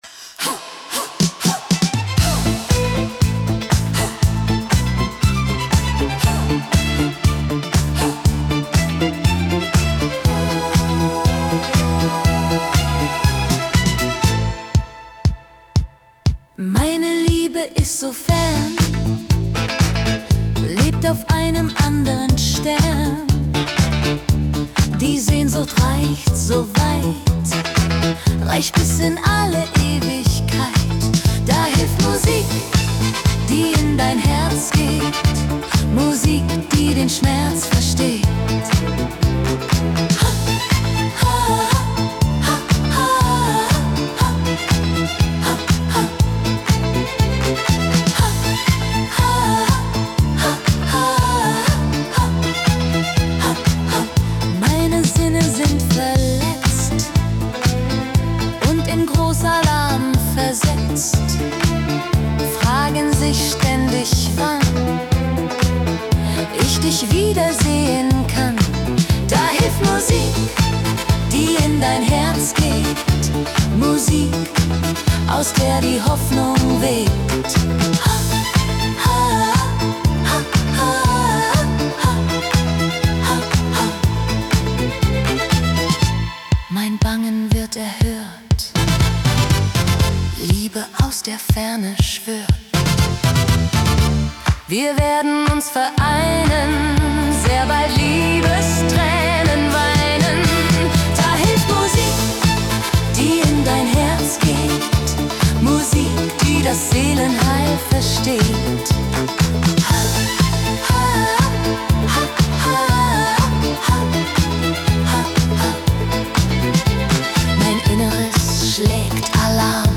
Meine Lieder
Hier findet man mein Gedicht Gen Süden in vertonter Version.